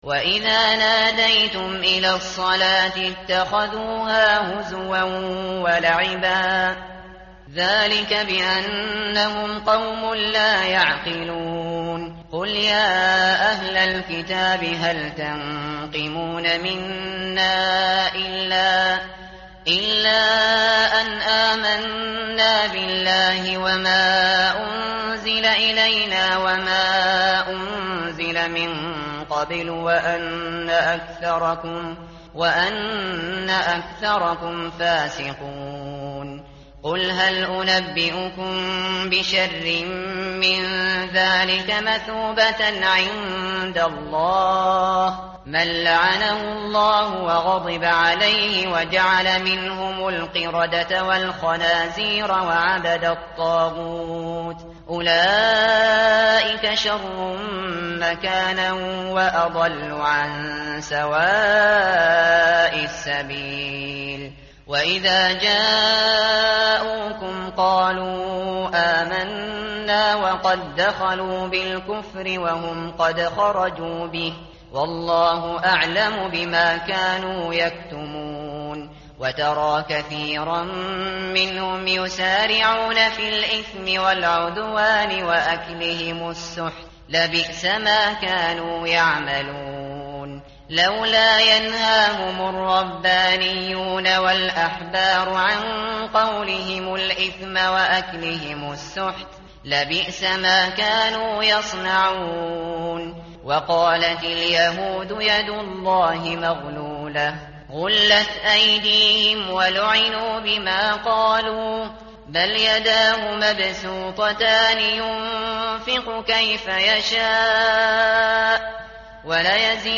tartil_shateri_page_118.mp3